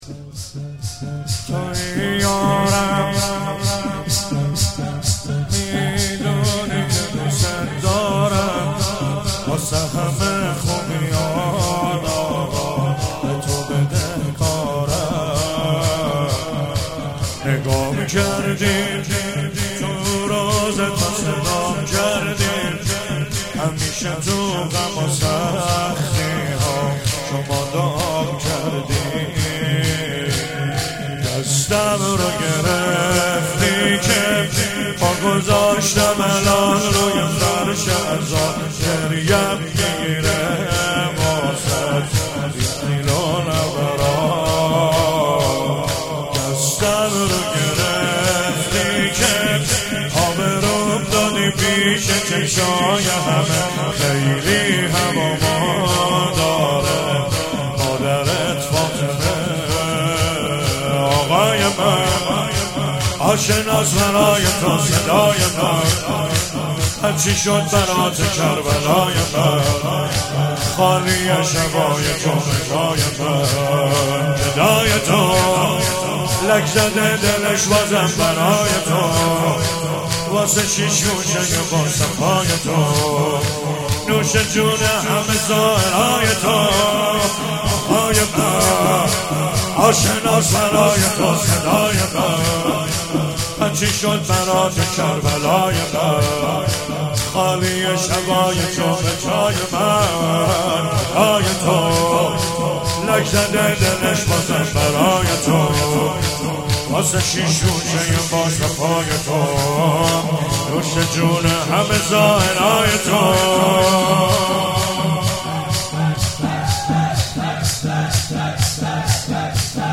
اول صفر 97 - شور - تویی یارم میدونی که
شور